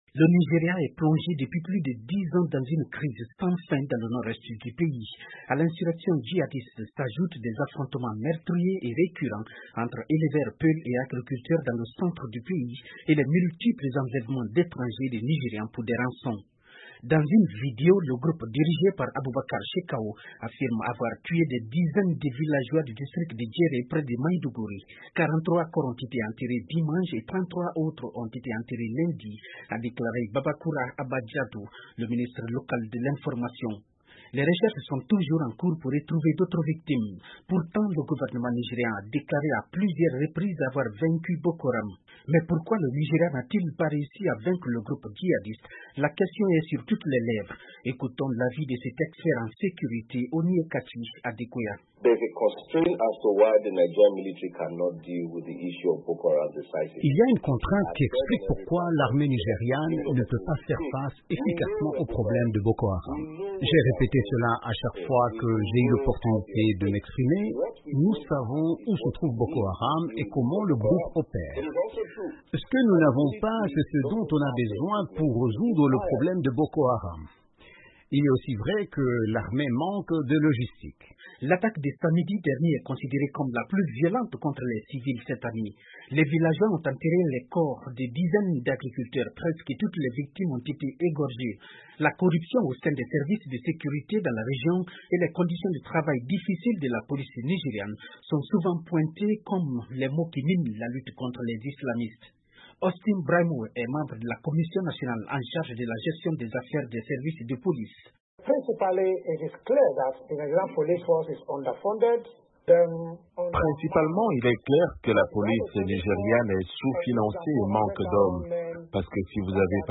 Pourquoi le pays n'a pas encore réussi à vaincre Boko Haram alors le gouvernement nigérian a déclaré à plusieurs reprises avoir vaincu Boko Haram. Le reportage